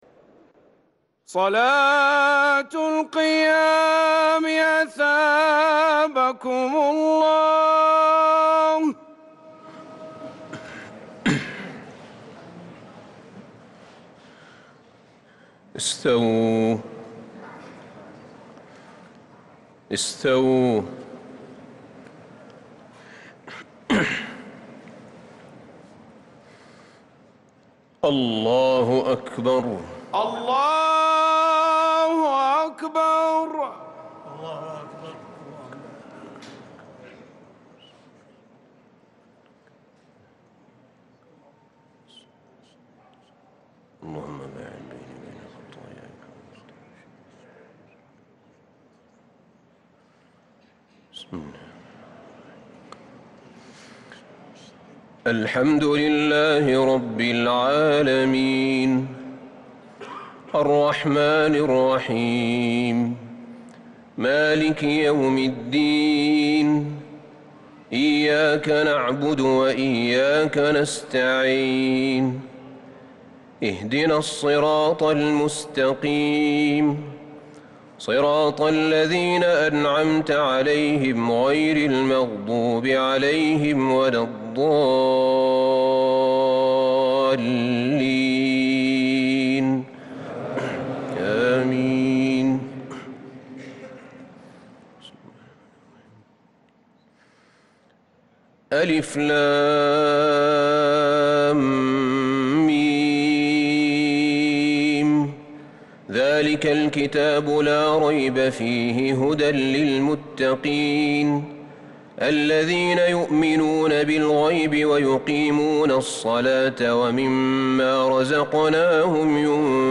تراويح ليلة 1 رمضان 1443 من سورة البقرة {1-66} Taraweeh 1st night Ramadan 1443H > تراويح الحرم النبوي عام 1443 🕌 > التراويح - تلاوات الحرمين